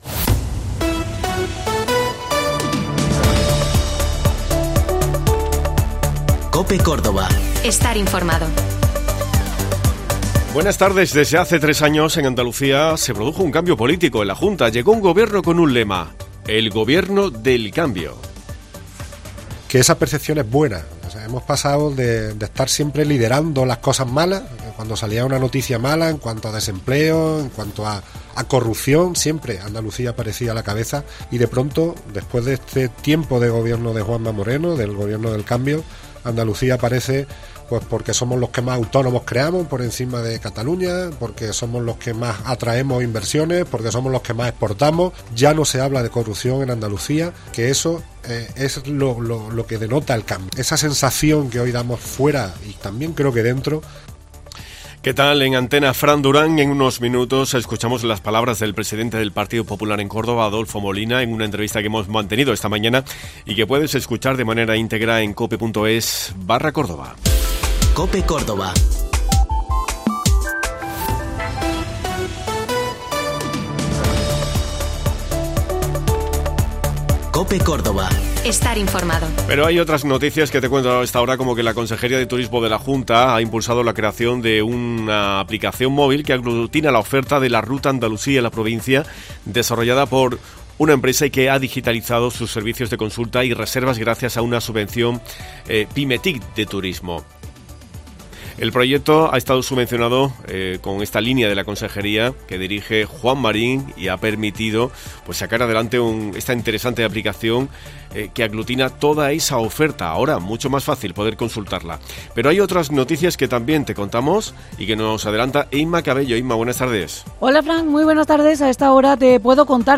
Hoy hacemos un recorrido por la entrevista que hemos mantenido en esta mañana de jueves.